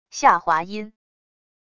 下滑音wav音频